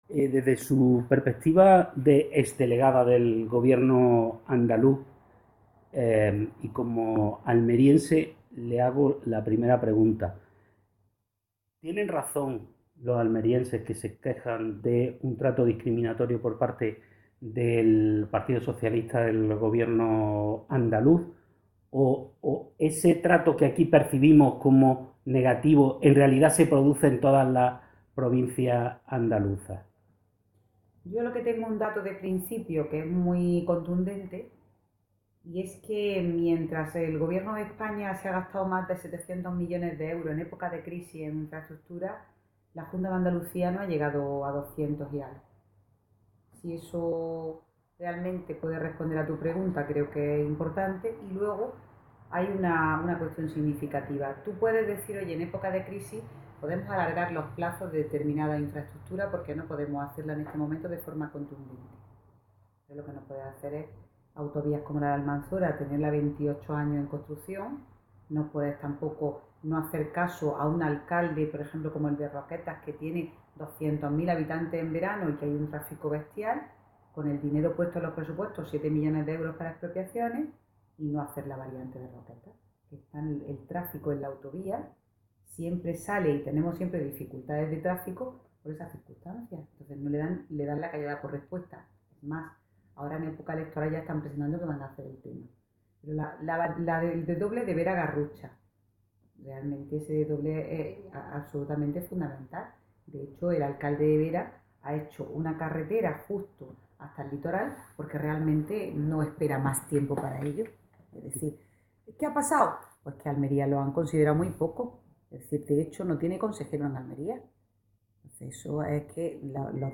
entrevistacrespo.mp3